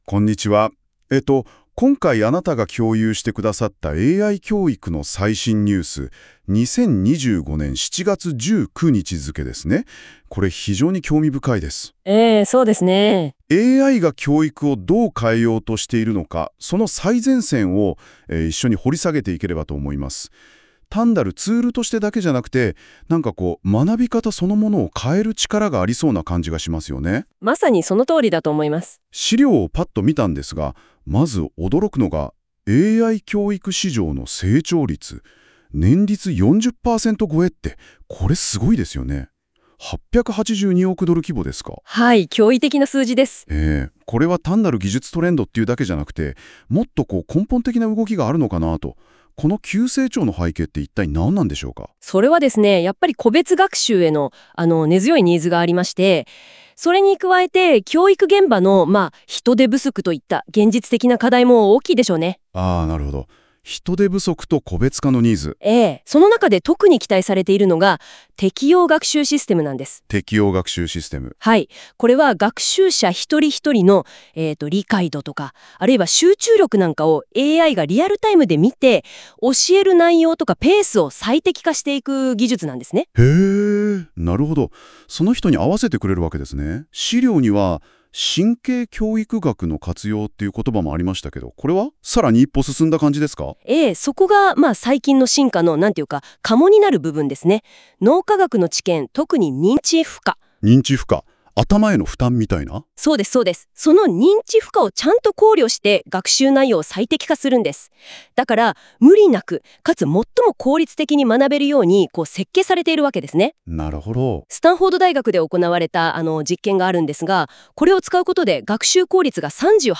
忙しい方のために、この記事をポッドキャスト形式で解説しています。通勤・移動中にもお聞きいただけます。
※ この音声は Google NotebookLM を使用して記事内容から生成されています。